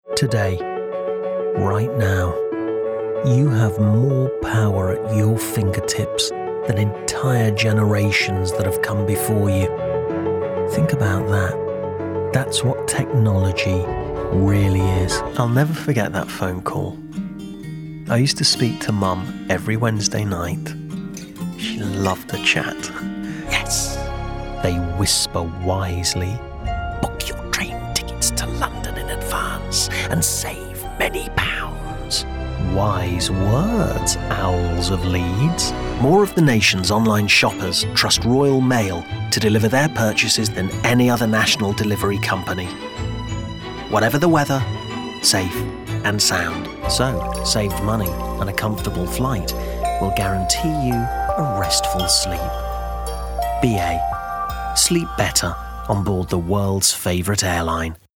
Voice Reel
Andy Nyman - Commercial Reel
Andy Nyman - Commercial Voicereel.mp3